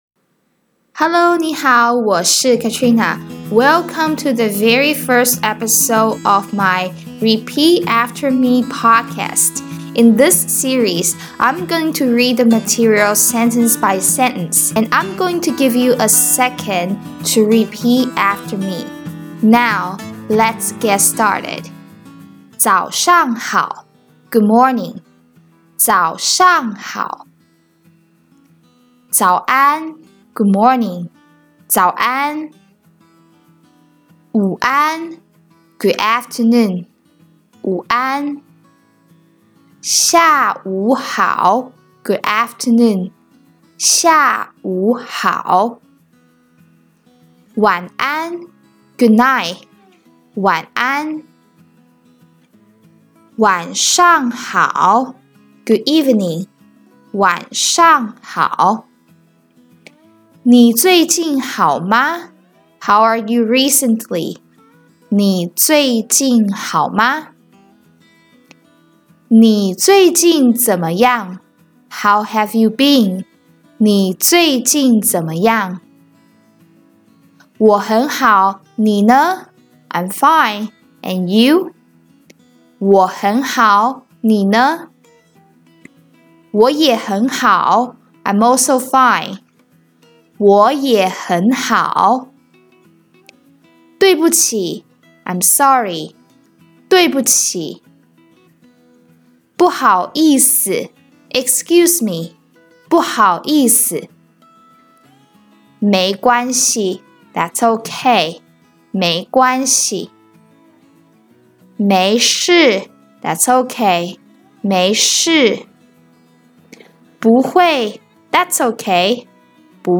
In each podcast, you'll be listen to each sentence one by one slowly which gives you enough time to repeat after each sentence.